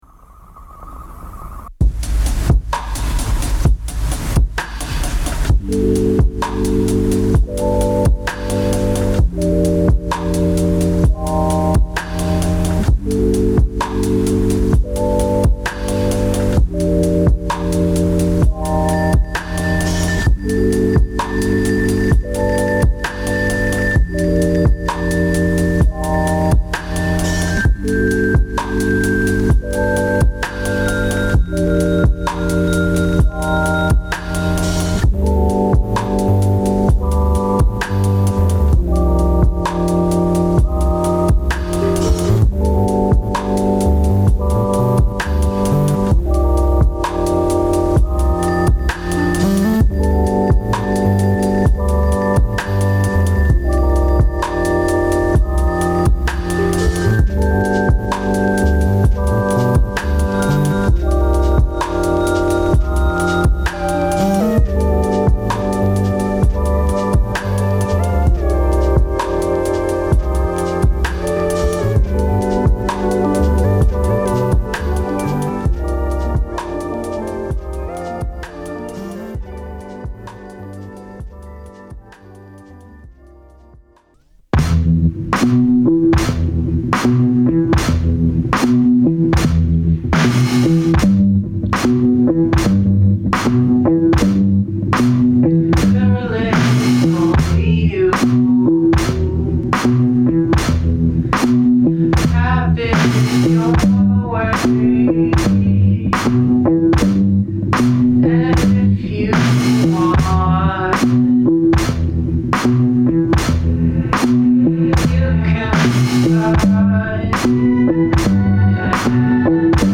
期待を裏切らないチル〜メロウ〜エレクトリックビーツがオススメです。